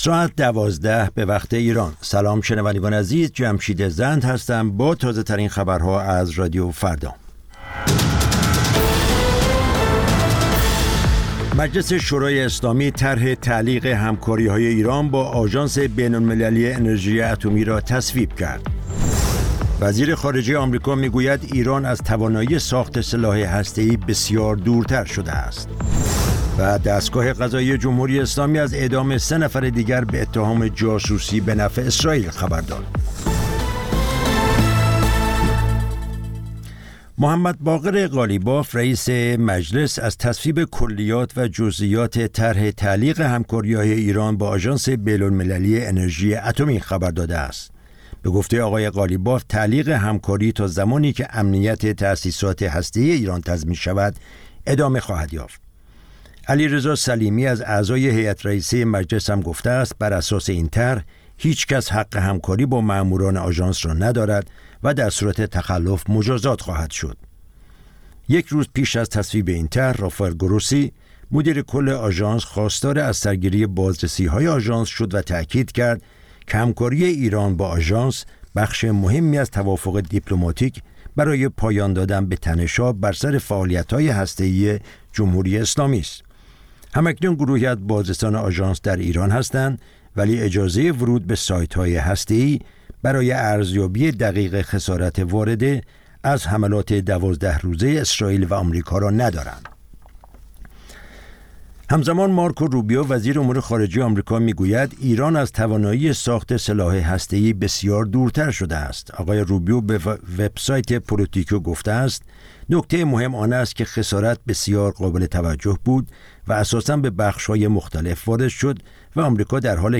سرخط خبرها ۱۲:۰۰
پخش زنده - پخش رادیویی